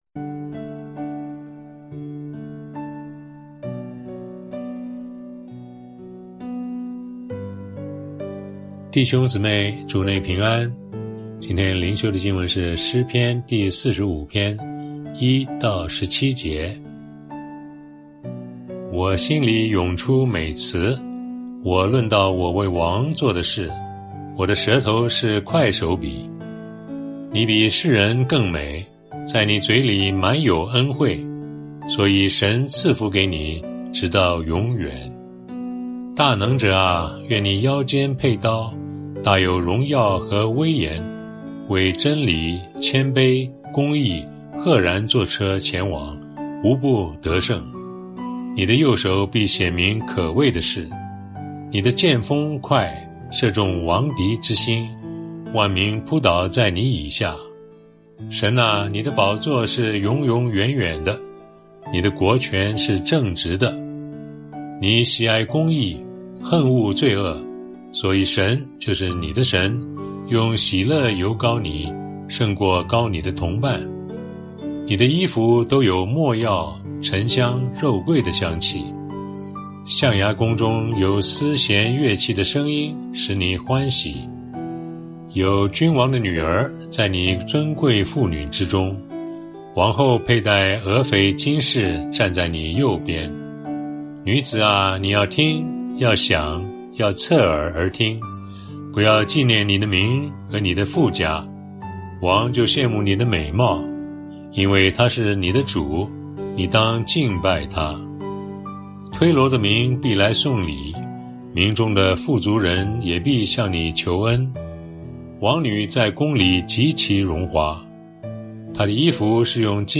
牧長同工分享：何等美的基督